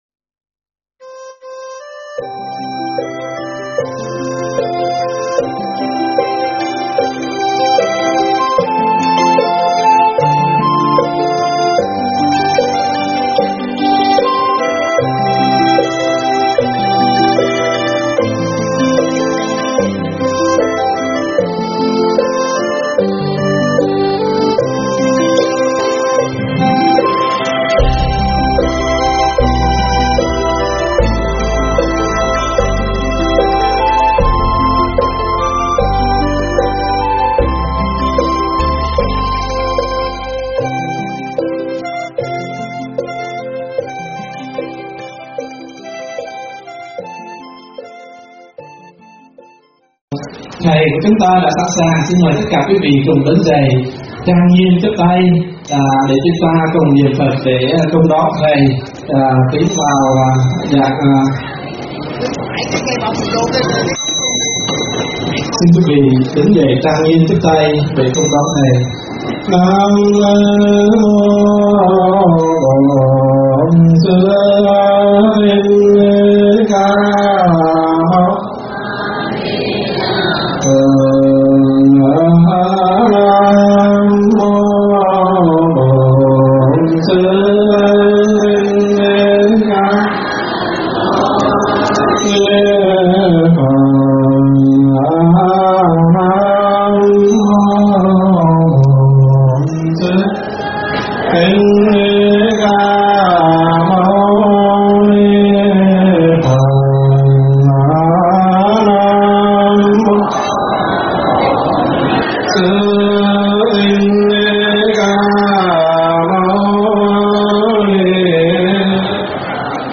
thuyết pháp
tại Chùa Bảo Phước